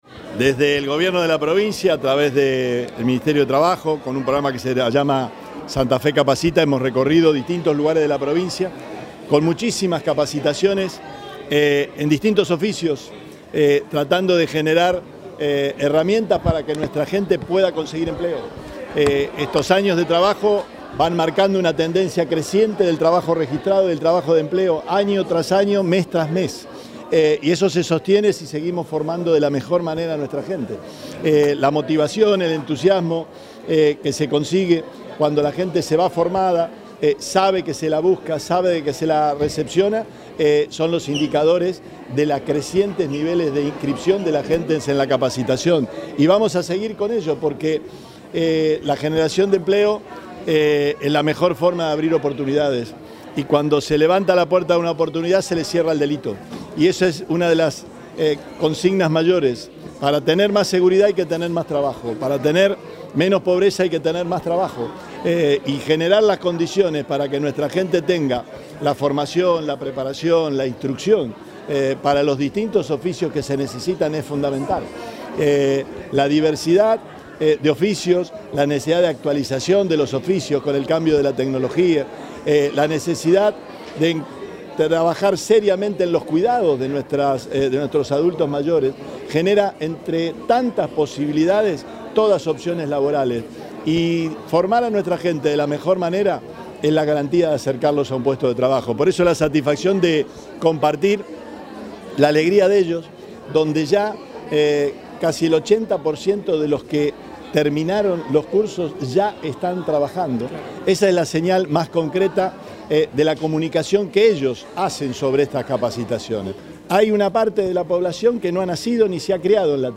Perotti entregó diplomas en Rosario, a los alumnos del programa Santa Fe Capacita
Declaraciones Perotti